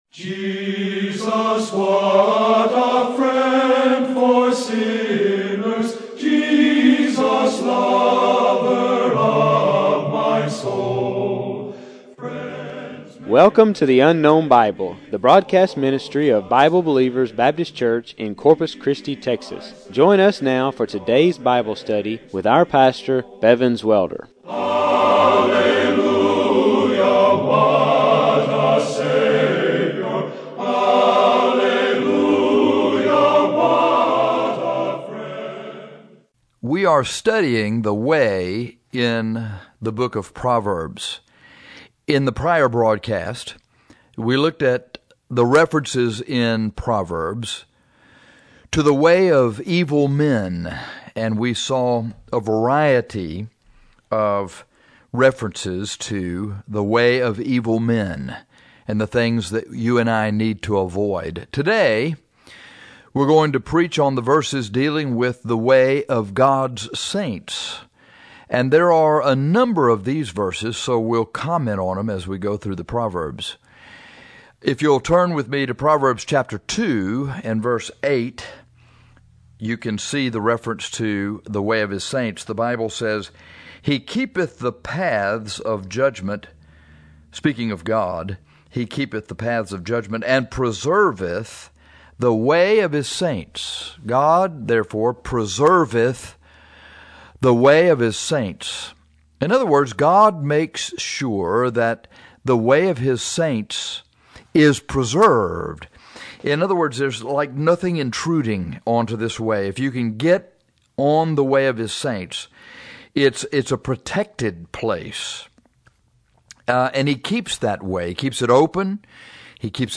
Today, we are going to preach on the verses dealing with the way of God’s saints.